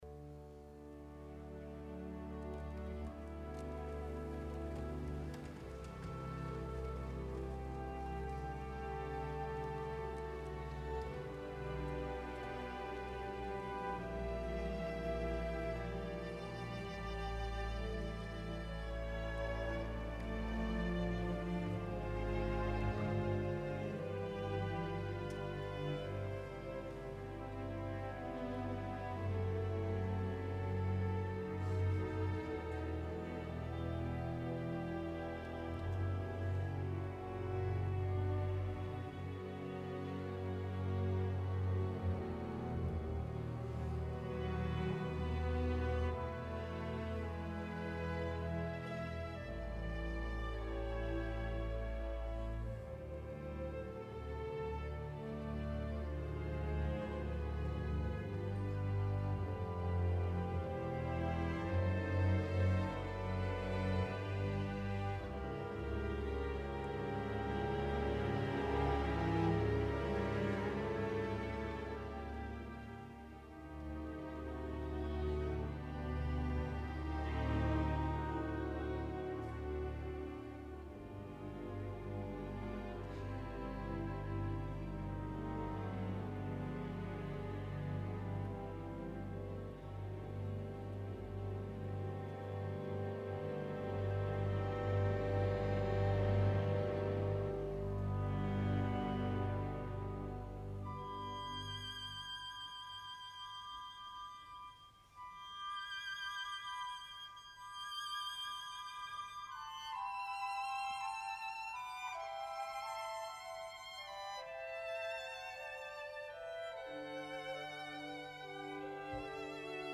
Avui us porto el que va dirigir Thielemann el passat 22 de març amb la participació del Chor des Bayerischen Rundfunks i un magnífic quartet solista, a banda de la Staaskapelle, que van interpretar la monumental Missa Solemnis de Ludwig van Beethoven.
Tot està sota control, tot és equilibrat, precís i d’una rotunditat aclaparadora. El só és imponent, tant de l’orquestra com el del cor, d’una precisió vocal que sembla fer fàcil l’extenuant i extrema partitura que Beethoven va reservar a la formació coral. La sensació final d’aquesta Missa Solemnis no deixa de semblar com la d’una màquina imponent de fer música amb resultats aclaparadors però potser sense ànima.